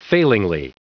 Prononciation du mot failingly en anglais (fichier audio)
Prononciation du mot : failingly